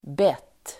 Uttal: [bet:]